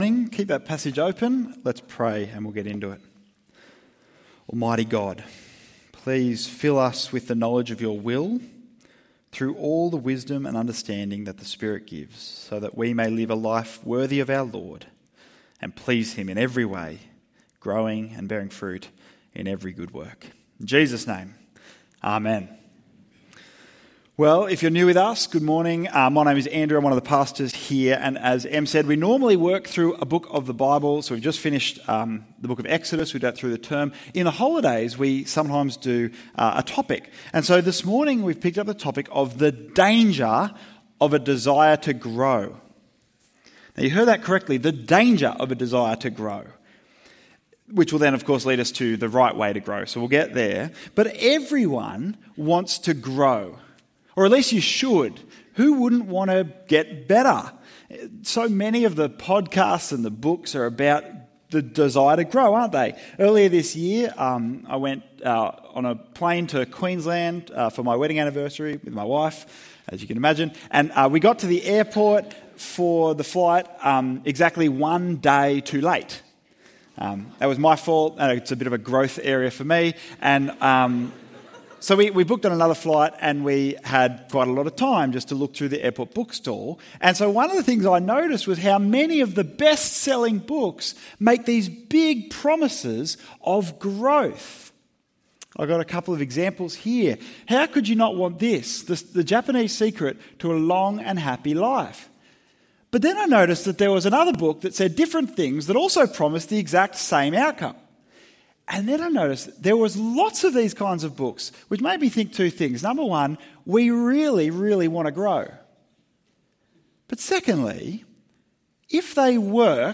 Religious rituals don't save. Faith in Jesus does. ~ EV Church Sermons Podcast